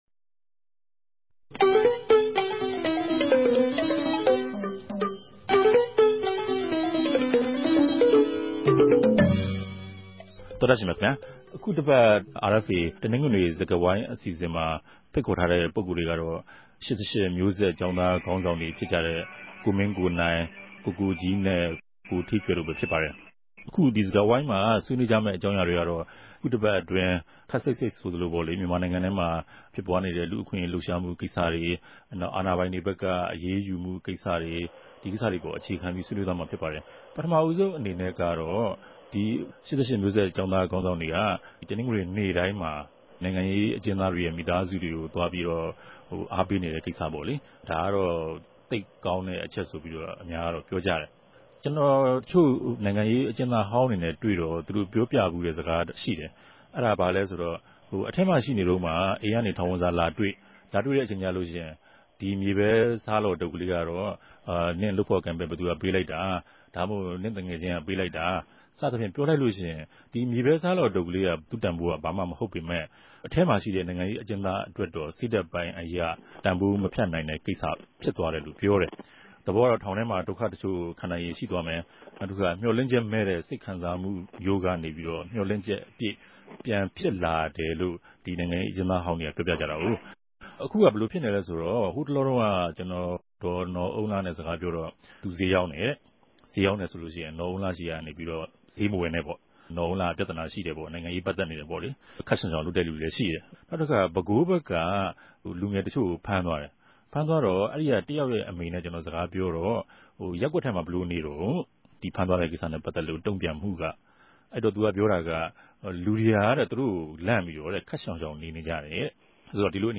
RFA ဝၝရြင်တန်႟ုံးခဵြပ် စတူဒီယိုကနေ
တယ်လီဖုန်းနဲႛ ဆက်သြယ်္ဘပီး သူတိုႛနဲႛအတူ ပၝဝင်ဆြေးေိံြးထားပၝတယ်၊၊
တနဂဿေိံြ ဆြေးေိံြးပြဲစကားဝိုင်း